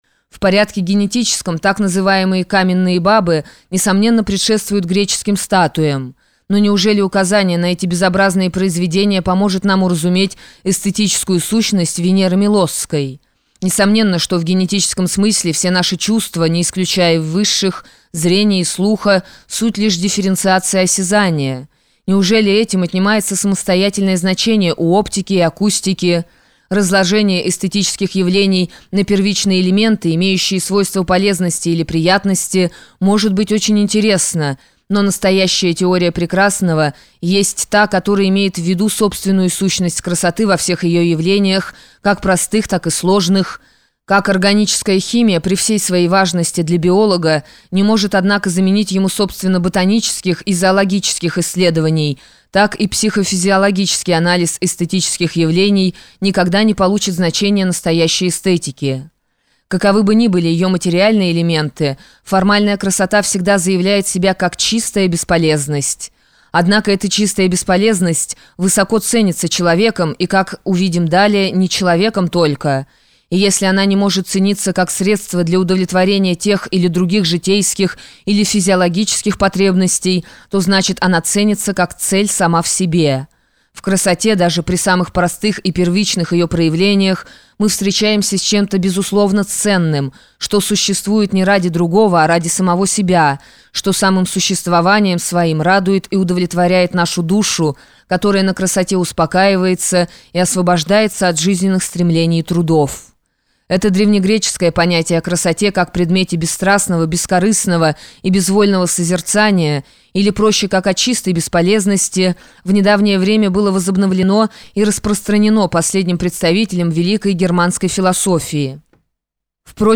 Скачать аудио книгу Краткая повесть об антихристе Соловьёв, Скачать аудио книгу бесплатно Краткая повесть об антихристе Соловьёв